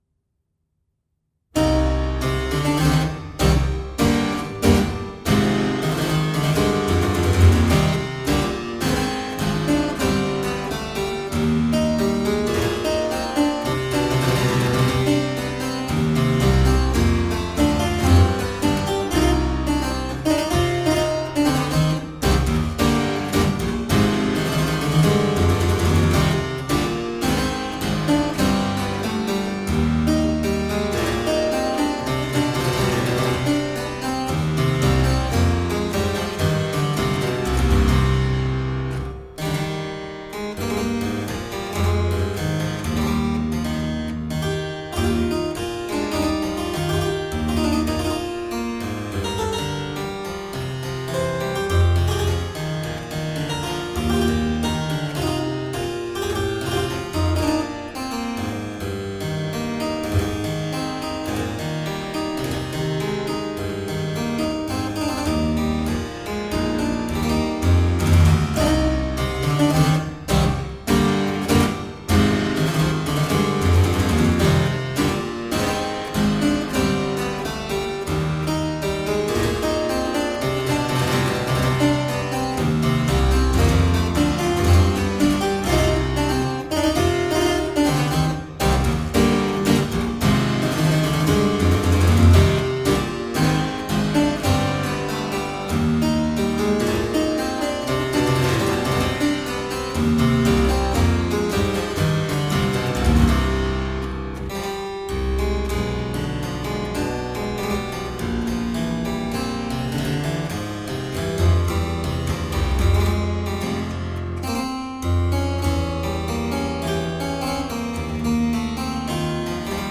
Vibrant harpsichord-music.
Classical, Baroque, Instrumental
Harpsichord